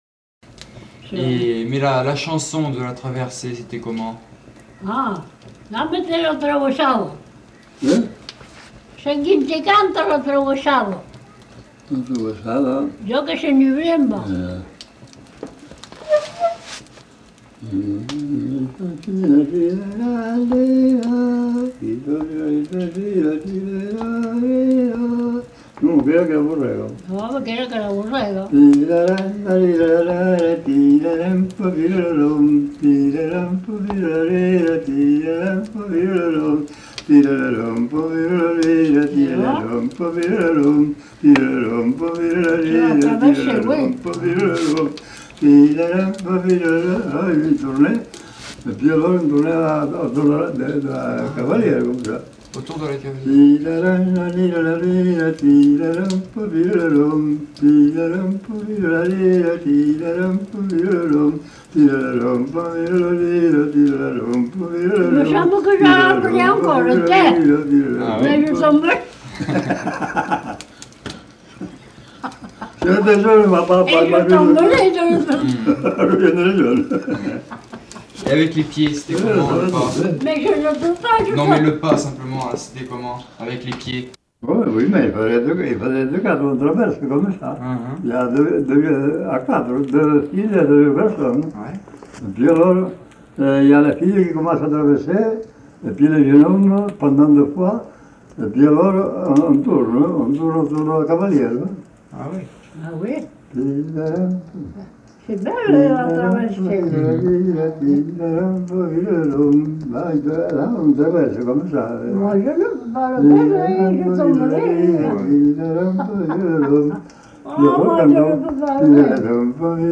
Aire culturelle : Couserans
Lieu : Uchentein
Genre : chant
Effectif : 1
Type de voix : voix d'homme
Production du son : fredonné
Classification : danses
Notes consultables : En fin de séquence description de la danse.